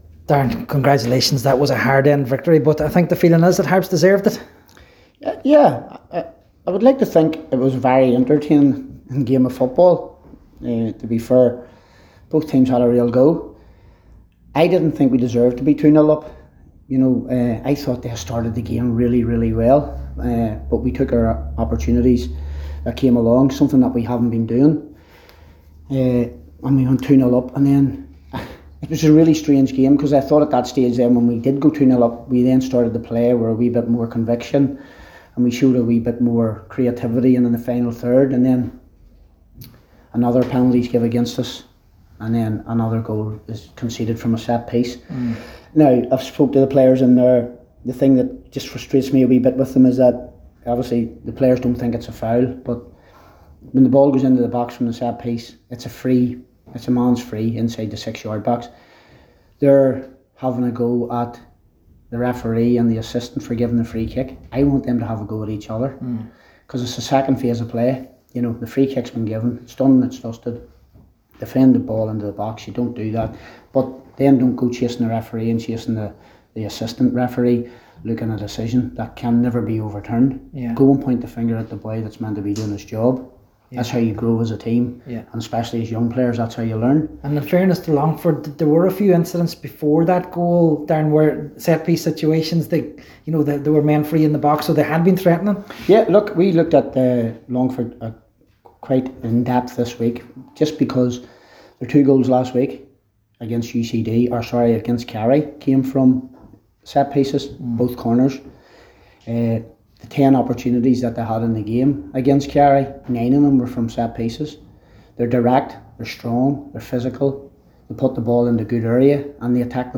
after full time in Ballybofey…